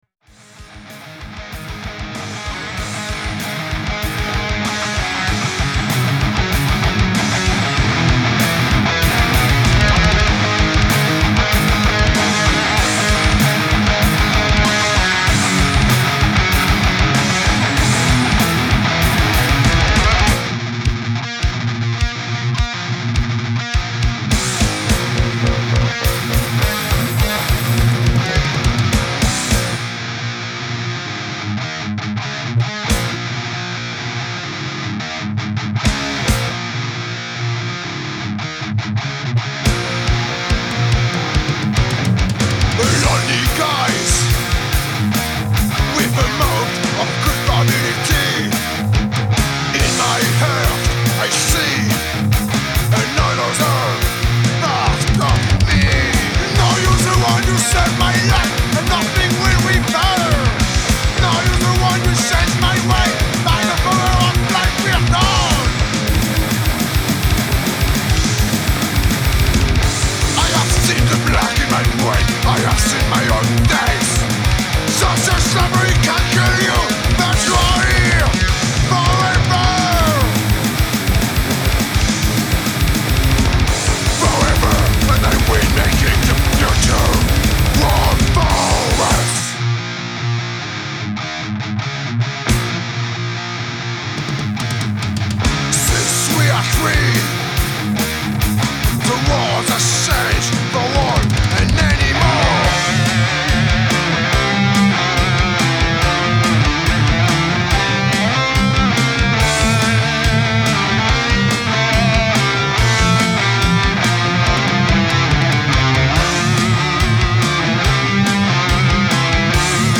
---  THRASH-METAL GAULOIS - UN PROJET NÉ À SAMAROBRIVA ---